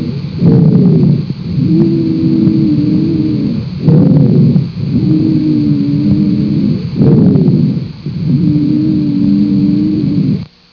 This means that during an asthma attack, it takes much longer to breathe out (expire) than it does to breathe in (inspire)
Click on the button to listen to what breathing sounds like during an asthma attack when a doctor or nurse listens with a stethescope.
wheeze.au